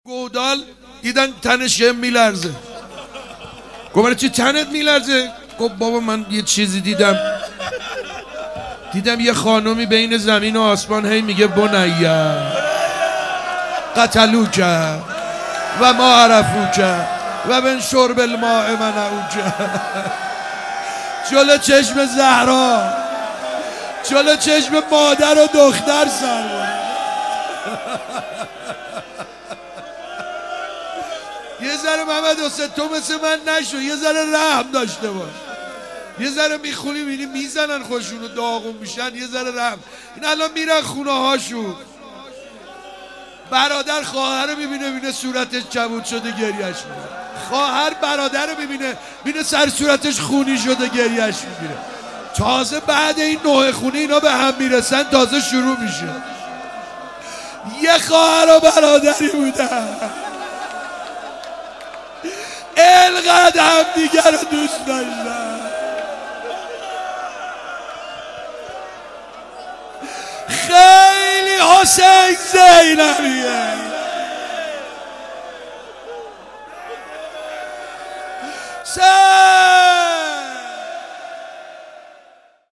روزدهم محرم95_روضه_بخش پنجم